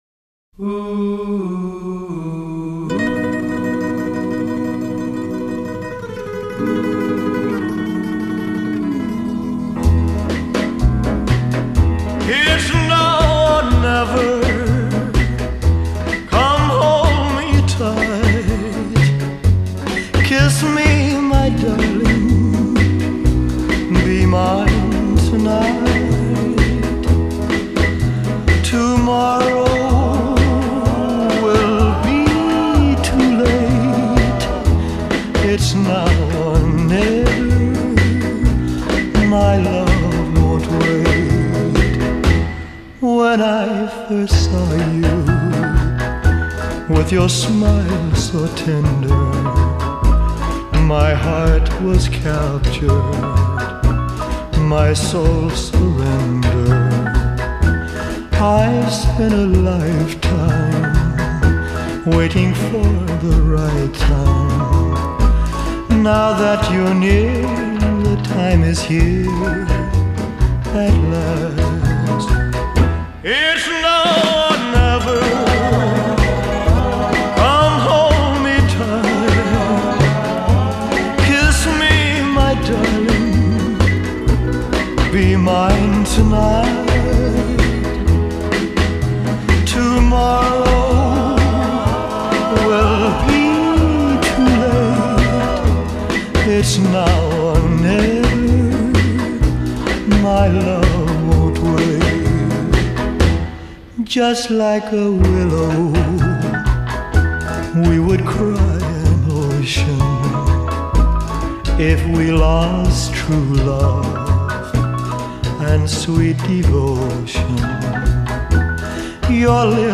Rock and Roll, Rockabilly